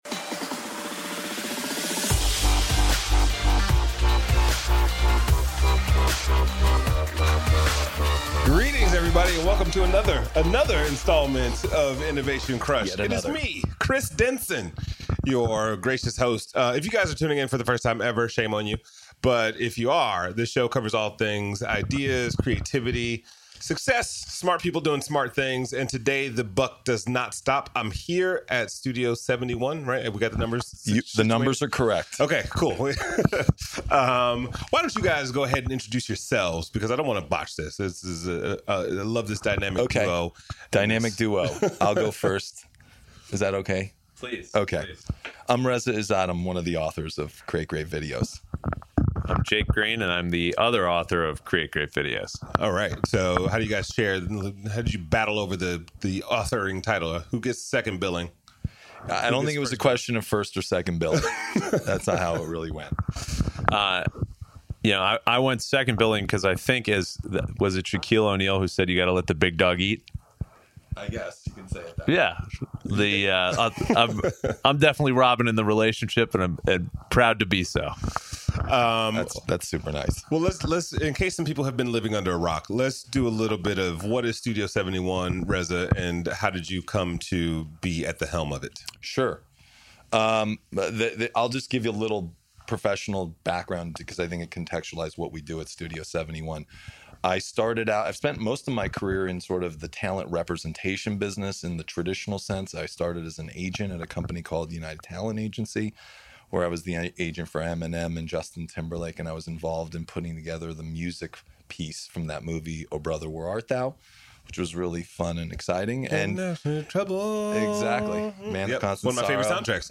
**note: please forgive a little of the mic handling noise in this episode!